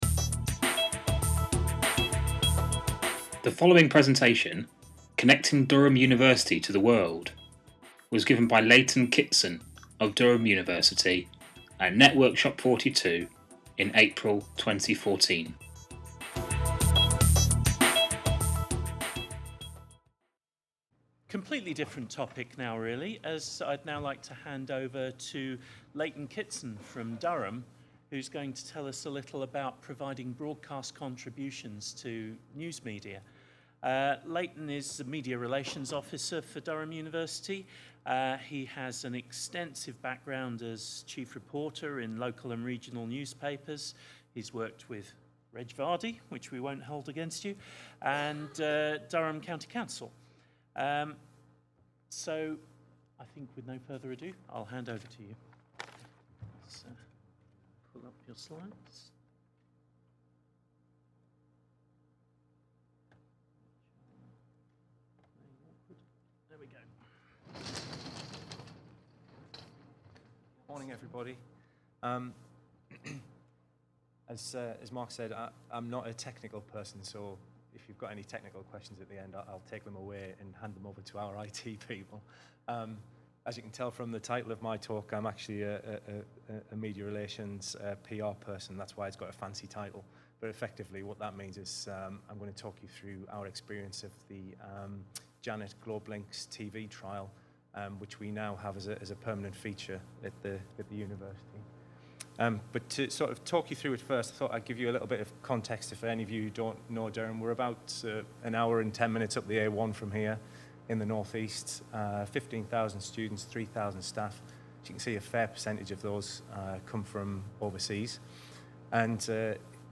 Networkshop 42
This presentation will briefly look at Durham’s strategic communications aims, provide an overview of the University’s experience of the Janet/Globelynx TV trial and how being able to offer down-the-line television facilities has helped directly connect the University with some of the world’s leading broadcasters.